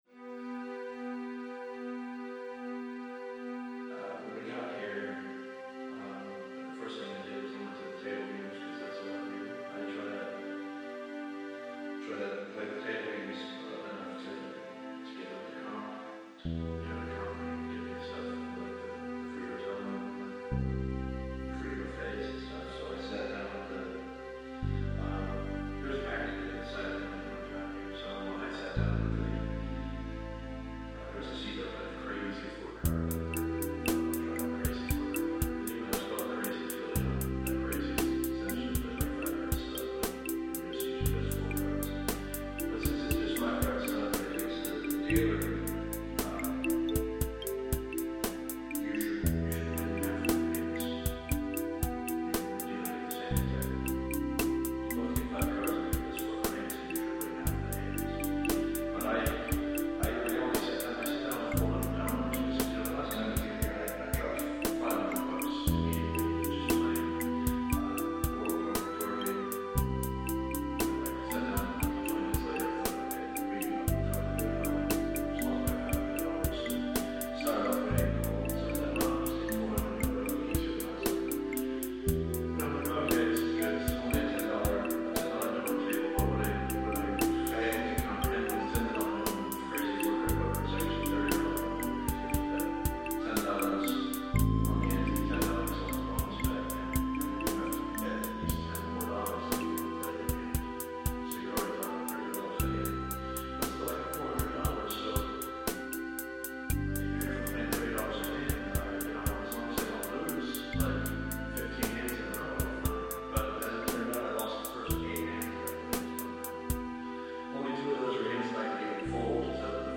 At any rate, I started writing some music that sounded like it could be a strange theme to the Karate Kid or some such. It is a simple additive composition. Drum loop made, bass laid, flute, etc. I wasn't too terribly pleased with it though.
The whole thing works better in headphones, and isn't really a proper song, but it makes me cry and giggle at the same time all the same.
Filed under: Instrumental | Comments (5)
I like the personal touch that the story in the background brings. a lot of people would have just found something random to use as the ambiance, but I think this is more unique and personal. kudos